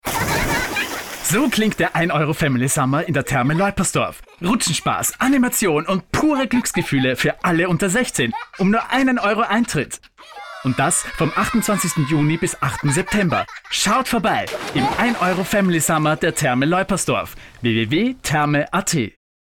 Wach, lebendig, resonierend, anpassungsfĂ€hig, dynamisch, gelassen
Sprechprobe: Sonstiges (Muttersprache):